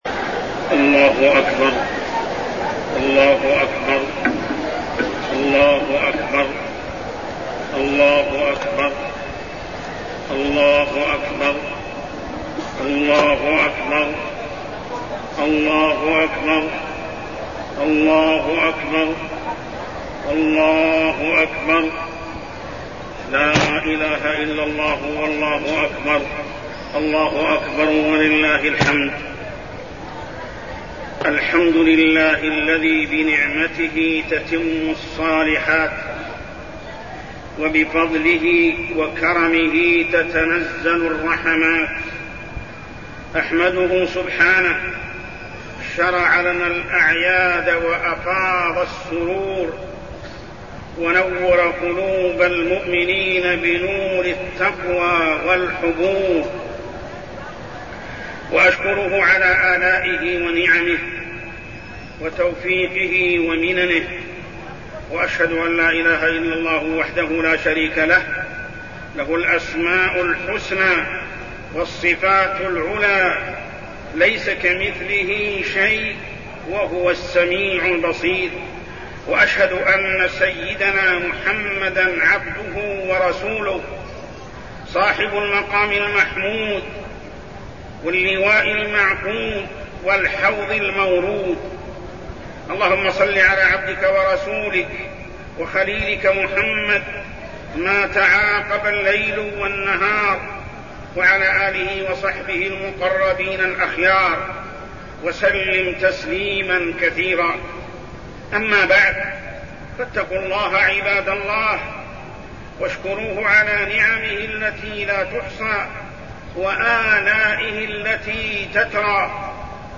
خطبة عيد الفطر -صرف أنواع العبادة لله
تاريخ النشر ١ شوال ١٤١٢ هـ المكان: المسجد الحرام الشيخ: محمد بن عبد الله السبيل محمد بن عبد الله السبيل خطبة عيد الفطر -صرف أنواع العبادة لله